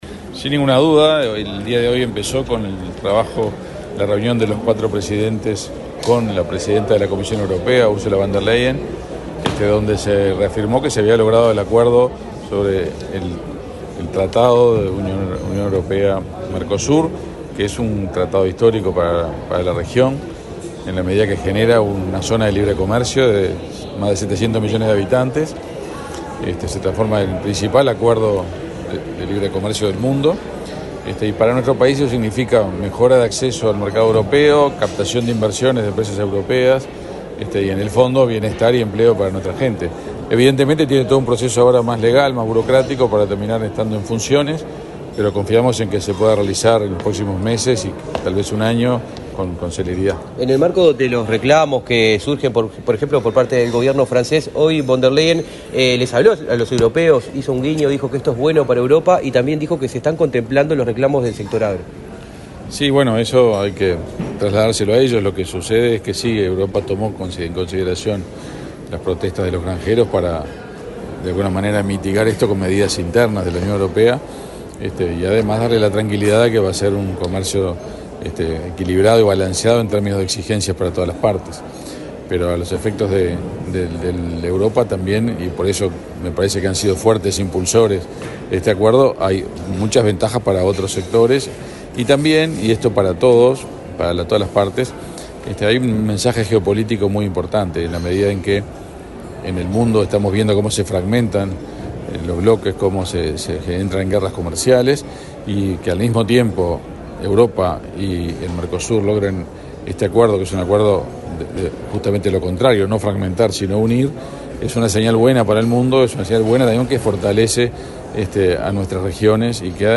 Declaraciones del canciller, Omar Paganini
Declaraciones del canciller, Omar Paganini 06/12/2024 Compartir Facebook X Copiar enlace WhatsApp LinkedIn El canciller uruguayo, Omar Paganini, dialogó con la prensa, luego de participar en la LXV Cumbre de Presidentes de los Estados Parte del Mercosur y Estados Asociados, que se realizó este viernes 6 en Montevideo.